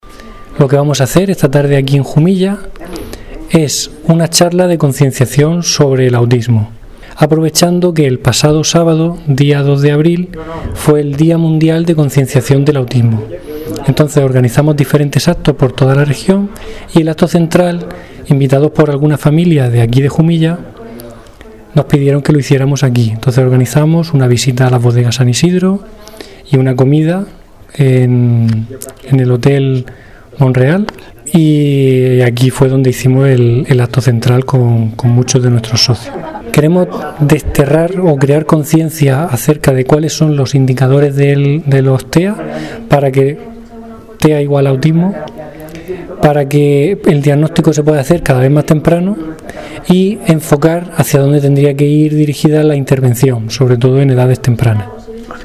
El pasado viernes tuvo lugar una charla informativa sobre Autismo: Diagnóstico, Etiología y Tratamiento